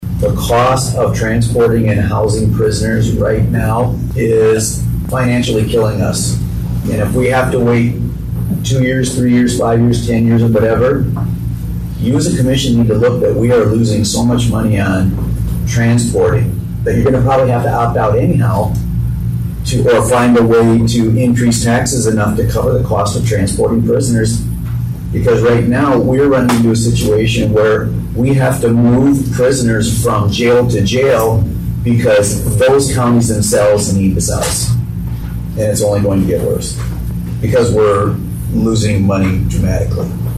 Hare told the commission they need to consider the escalating cost of transporting prisoners.